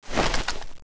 draw.wav